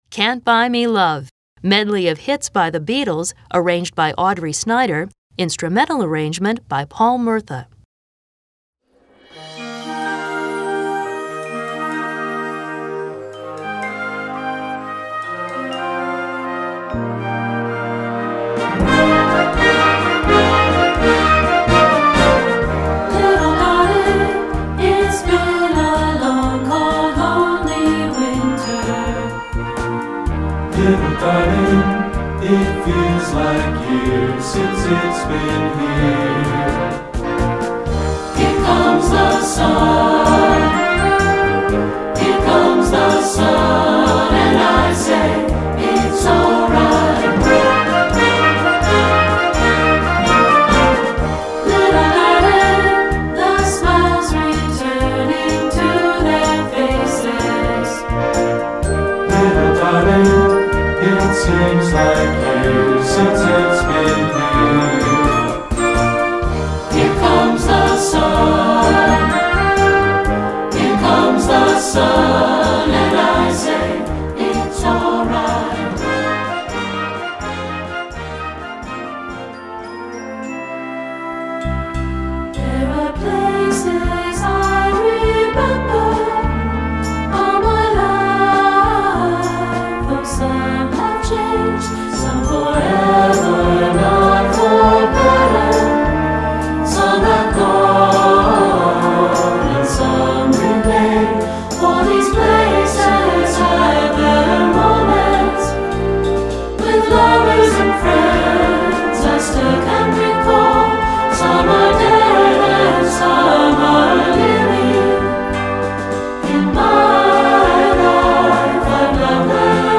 Concert Band
This easy-to-sing 5-minute medley
Voicing
SAB